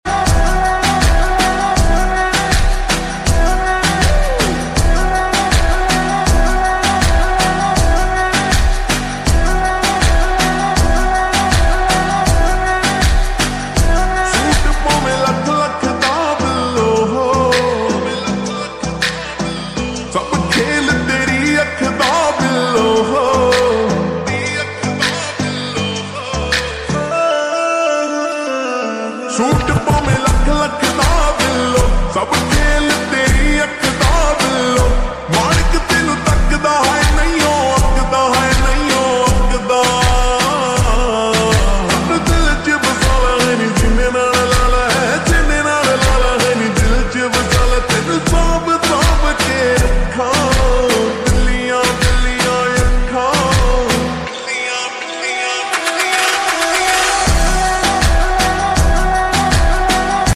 Full Song slowed reverb